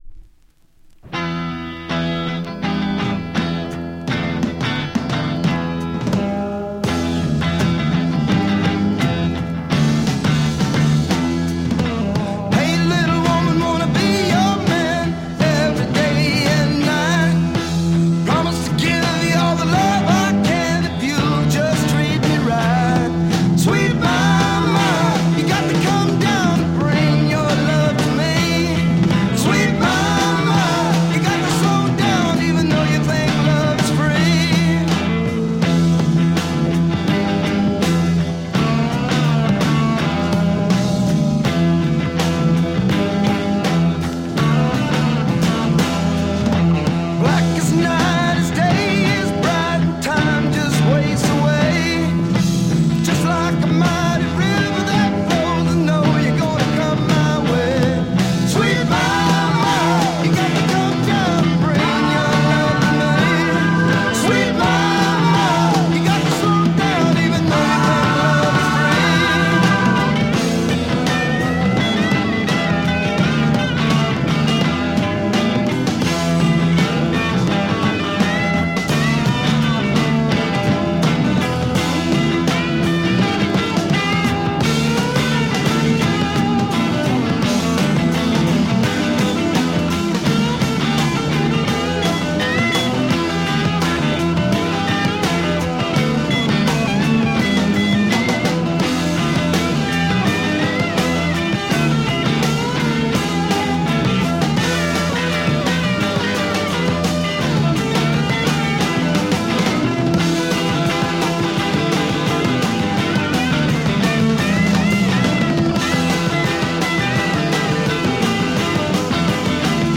Killer US Psych rock